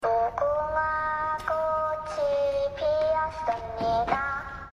Звук из игры в кальмара - чистый звук куклы (тише едешь дальше будешь)